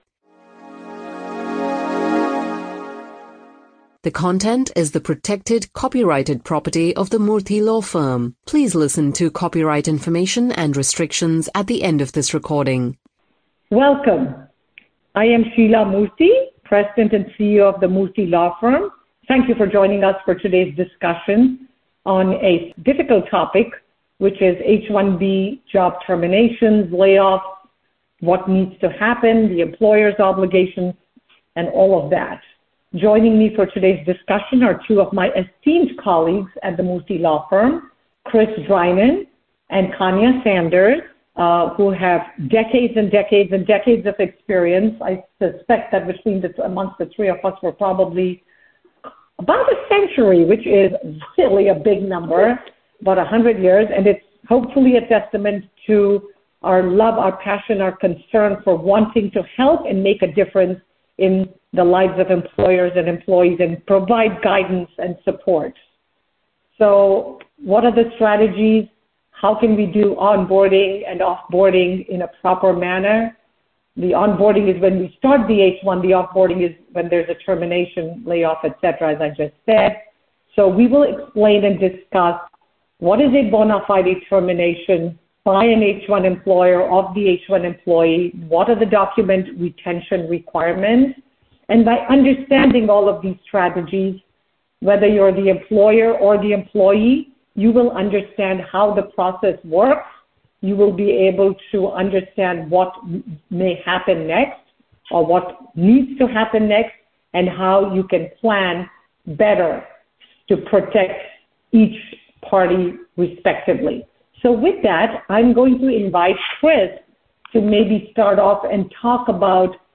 The employer’s legal requirements for a layoff of an H1B worker and trends in this administration are discussed by Murthy Law Firm attorneys in this 01.Oct.2025 podcast.